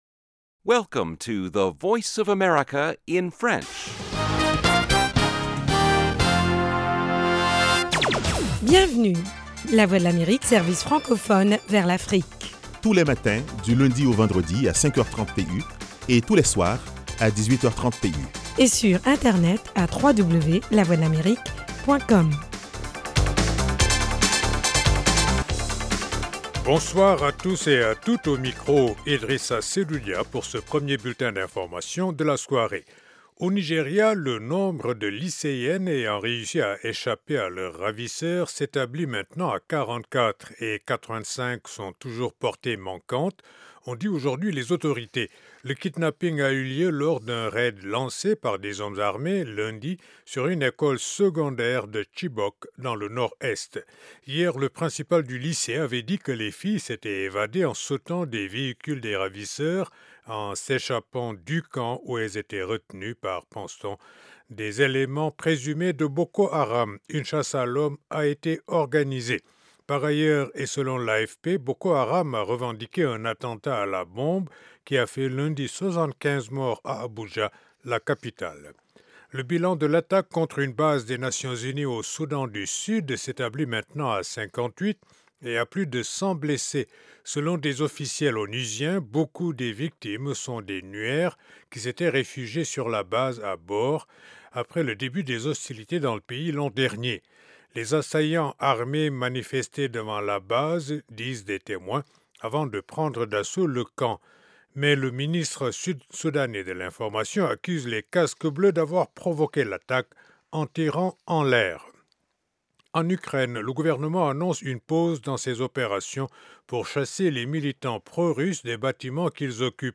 Le magazine vous tient aussi au courant des dernières découvertes en matière de technologie et de recherche médicale. Carrefour Santé et Sciences vous propose aussi des reportages sur le terrain concernant les maladies endémiques du continent : paludisme, sida, polio, grippe aviaire…